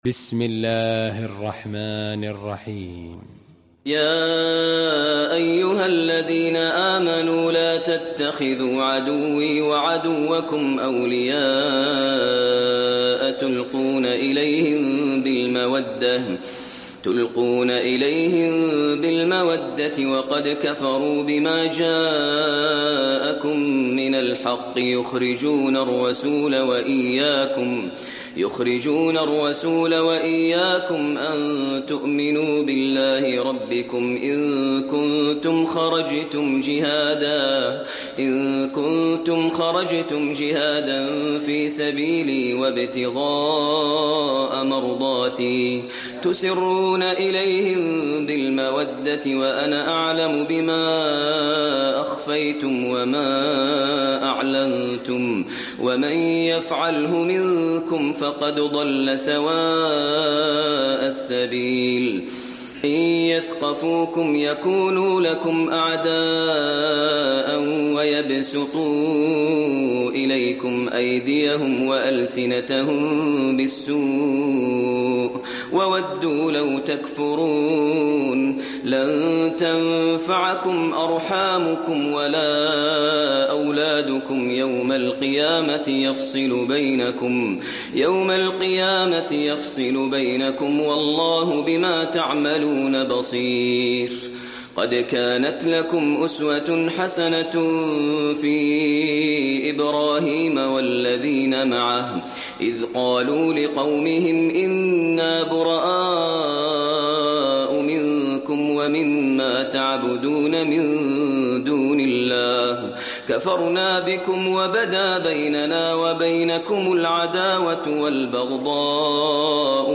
المكان: المسجد النبوي الممتحنة